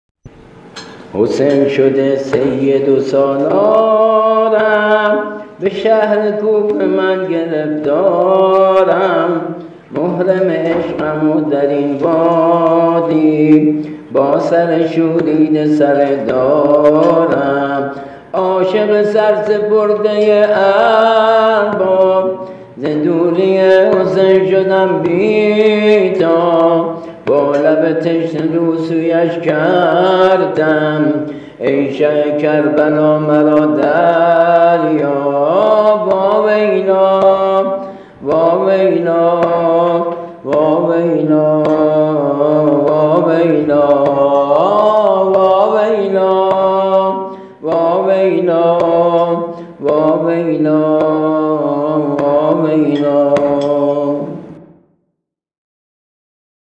◾نوحه زمینه سینه زنی